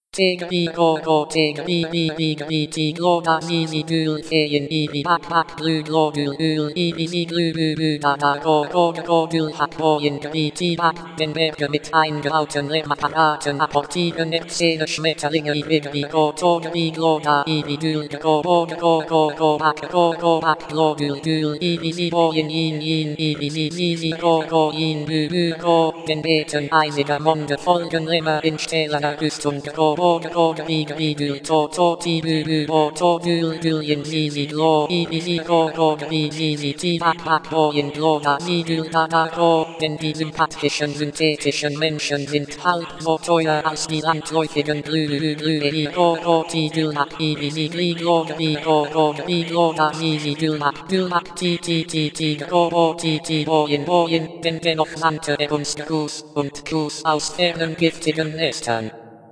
Wrzuciłem do generatora i minimalnie obrobiłem w Audacity: